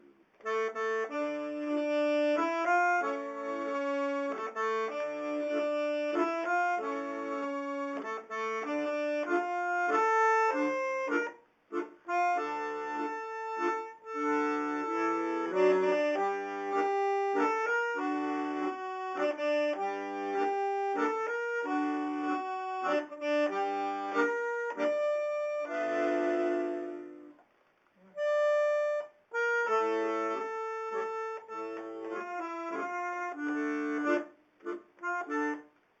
For one of them, a waltz called “Mazel”, (means ‘luck’, music by Abraham Ellstein, lyrics by Molly Picon, that go something like “Luck shines once on everyone, everyone but not me…”) we only have 2/3 of the music written out.
on my iPhone